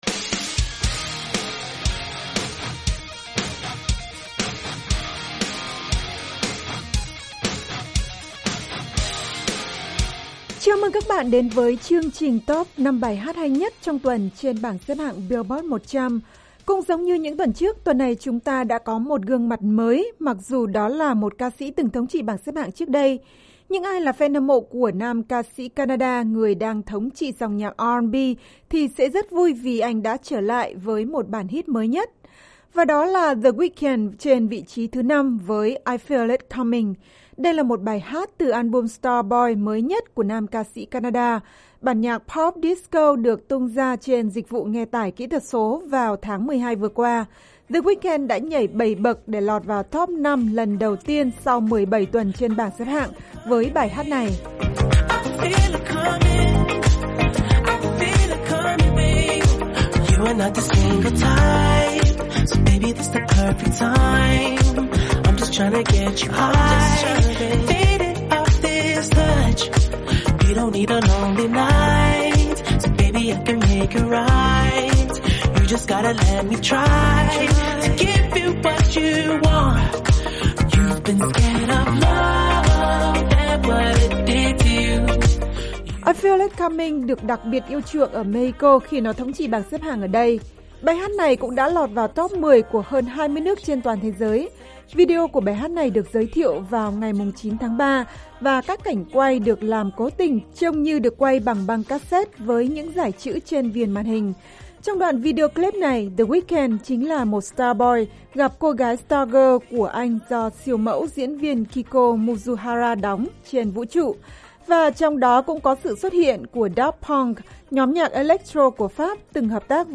bản ballad electro/R&B